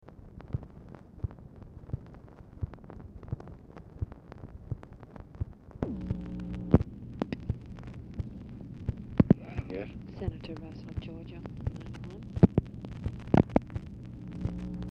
Telephone conversation # 10799, sound recording, LBJ and OFFICE SECRETARY, 9/19/1966, 11:30AM | Discover LBJ
Format Dictation belt
Oval Office or unknown location